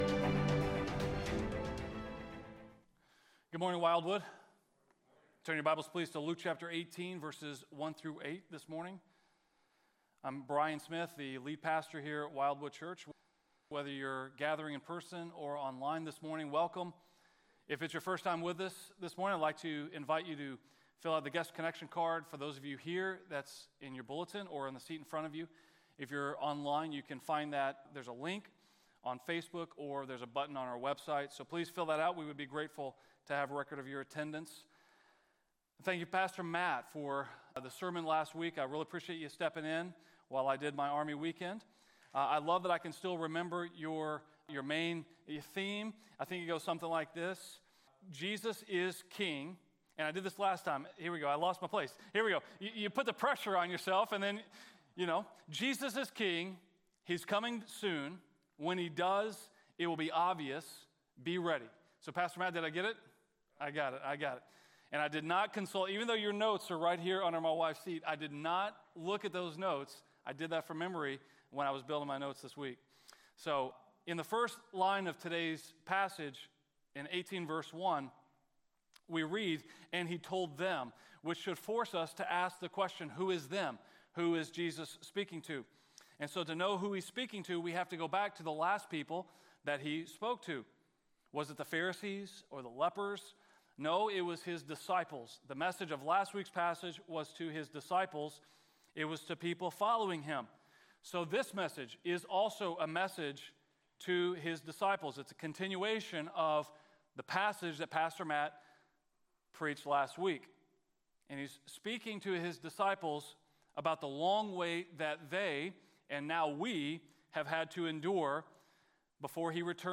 A message from the series "To Seek and To Save."